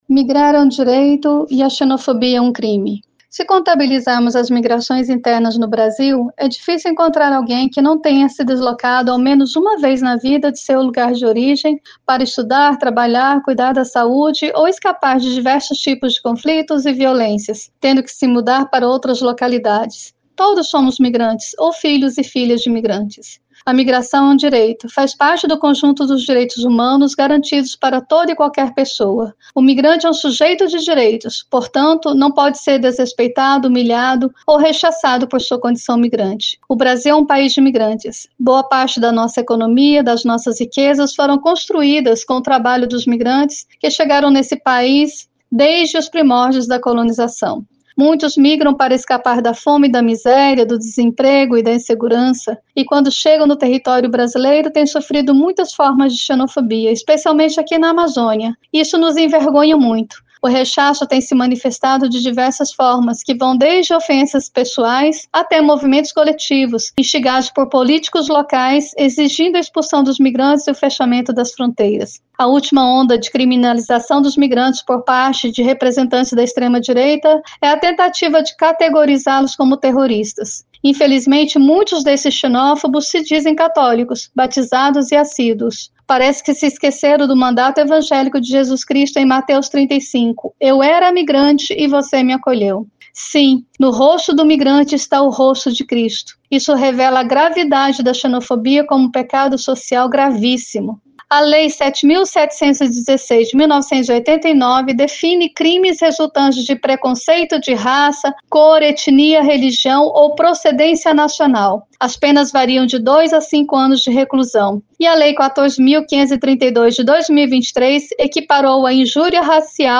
EDITORIAL.mp3.mpeg-1.mp3